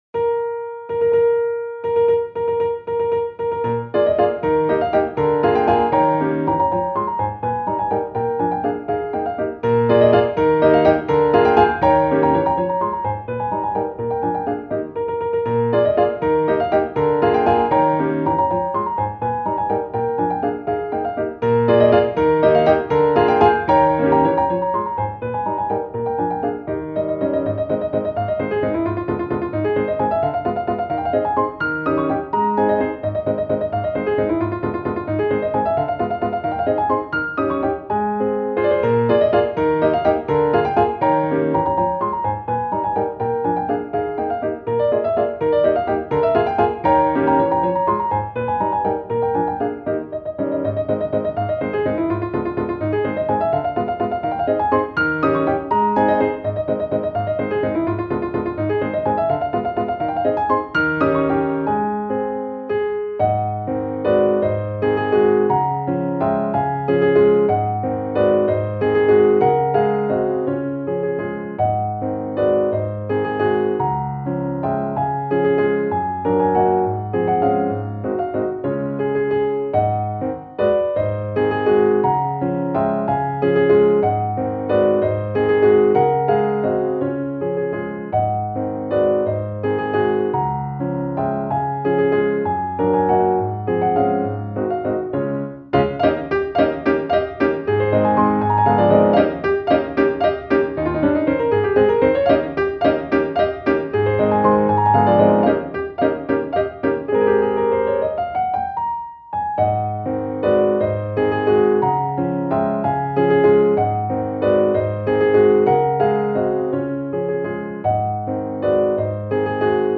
♪サウンドプログラマ制作の高品質クラシックピアノ。